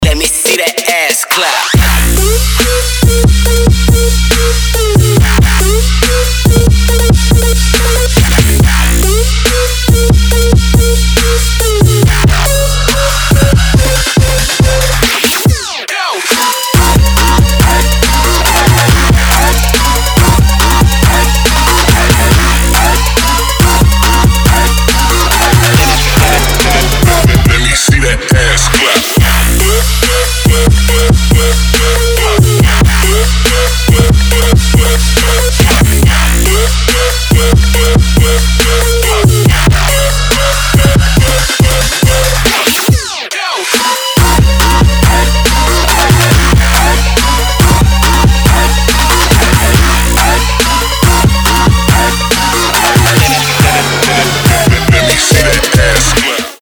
• Качество: 320, Stereo
Trap
CrunkStep